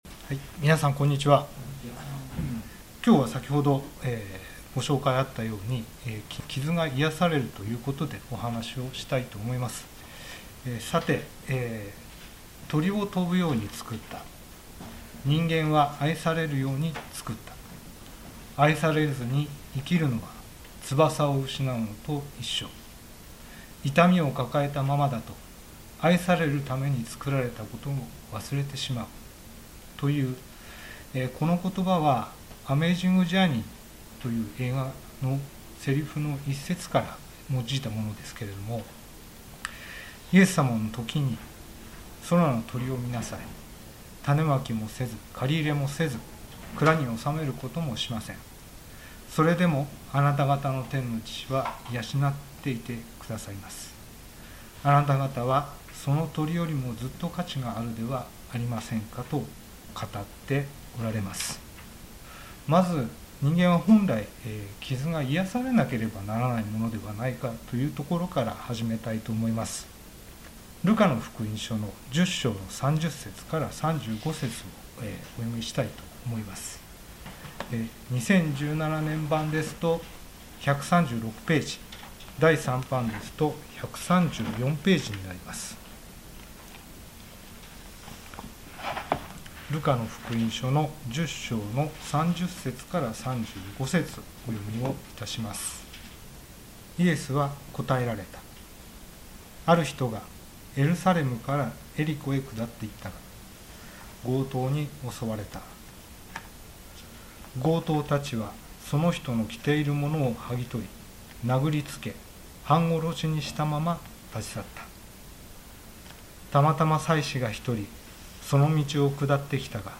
聖書メッセージ